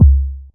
Bassdrum-02.wav